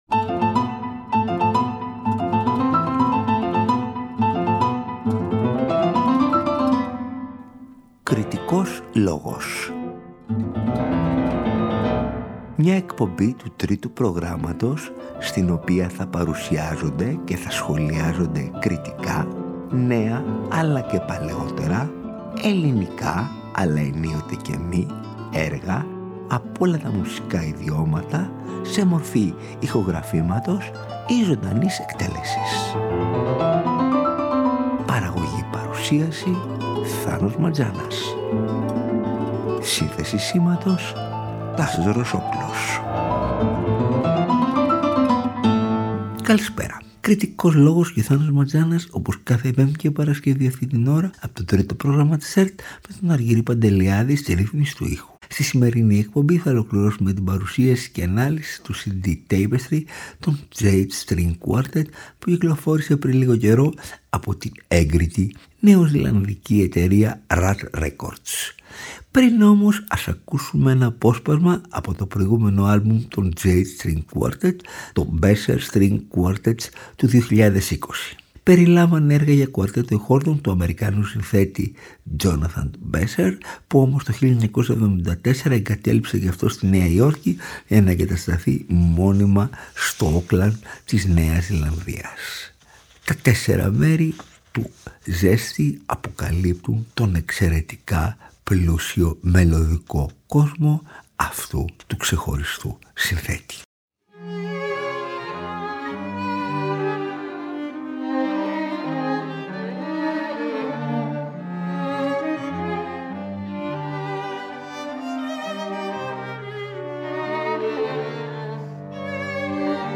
Οι νέες γραφές για κουαρτέτο εγχόρδων